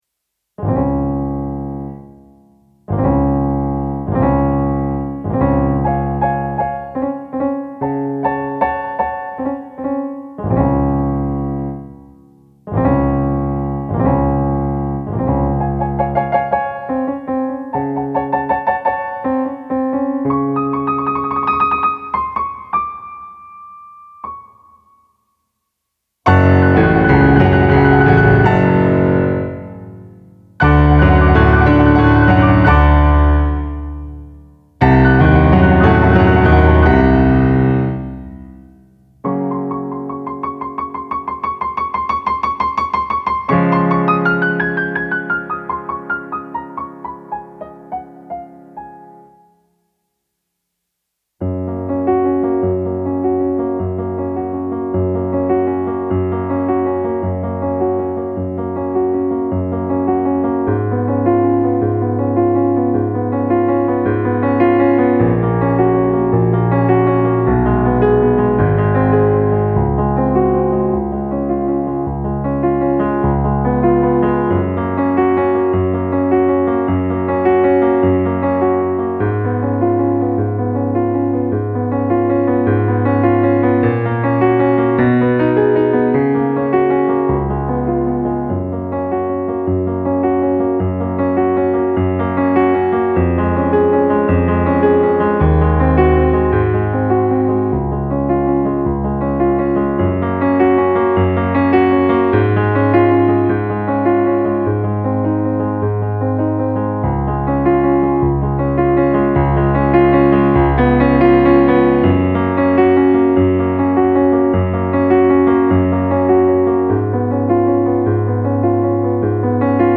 Va', pensiero - base pianoforte